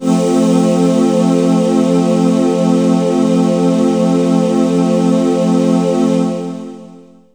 VOX_CHORAL_0008.wav